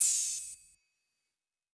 TS Open Hat 4.wav